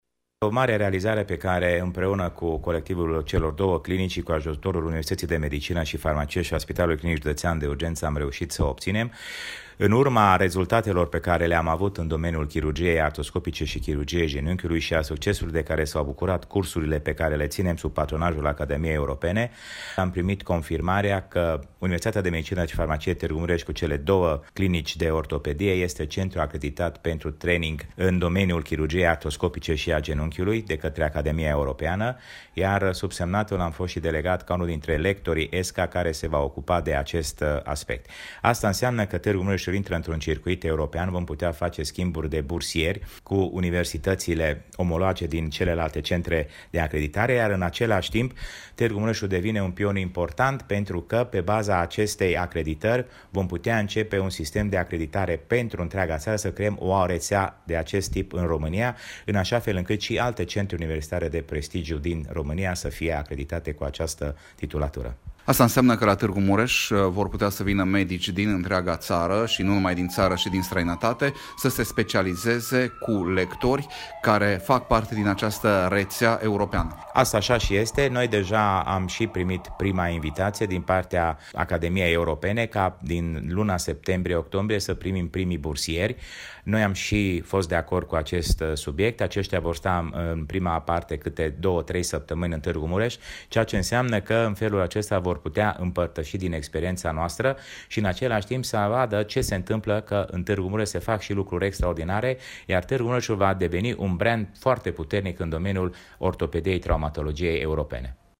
a stat de vorbă